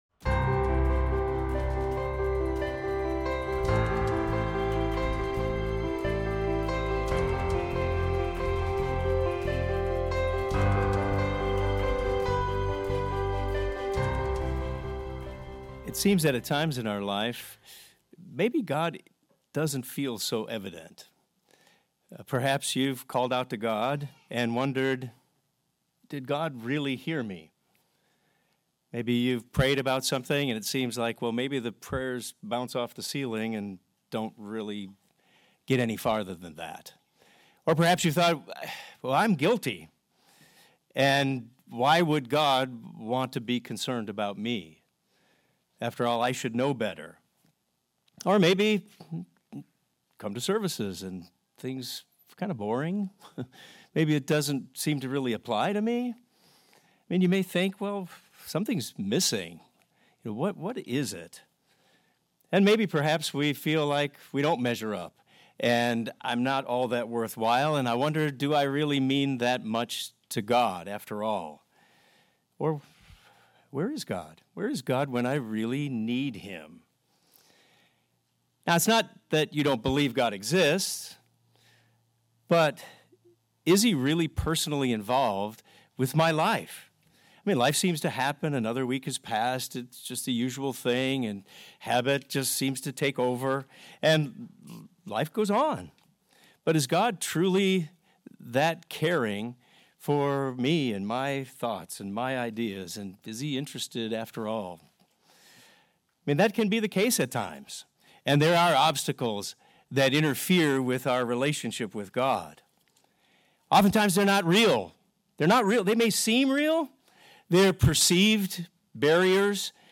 This sermon will detail God's perspective and our response to overcome obstacles.